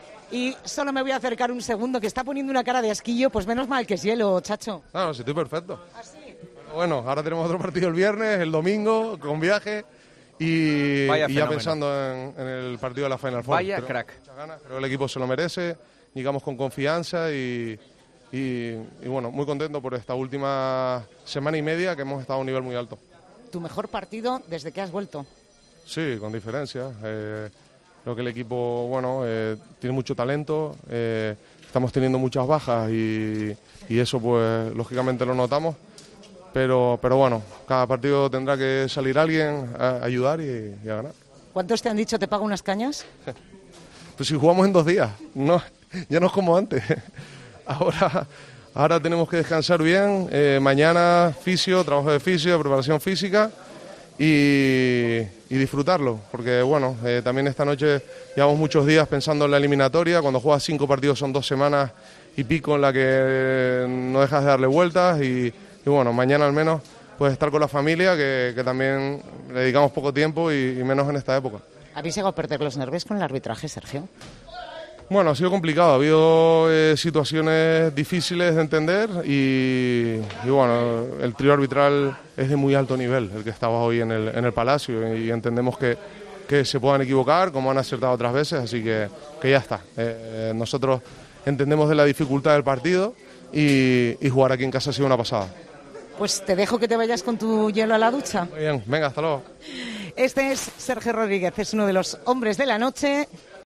AUDIO: Hablamos con el héroe de la remontada del Real Madrid ante el Partizan para meterse en la Final Four de la Euroliga.